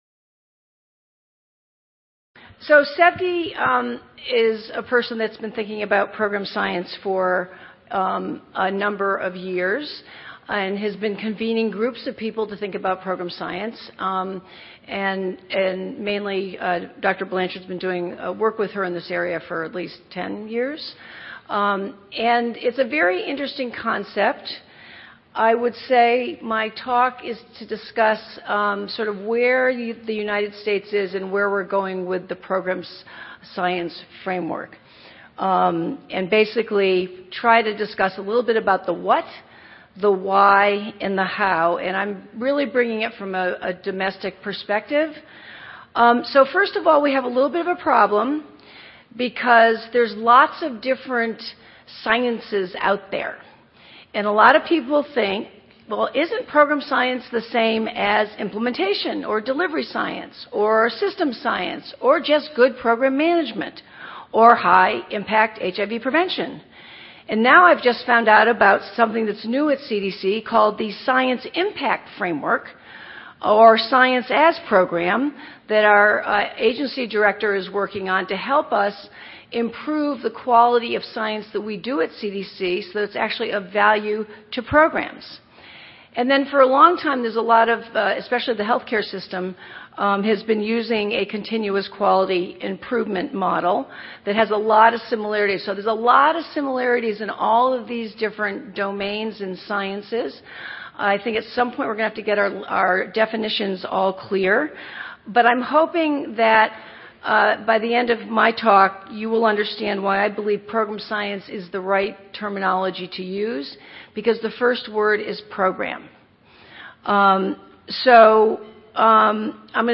See more of: Plenary Session 1: Program Science See more of: Oral and Poster << Previous Abstract | Next Abstract >>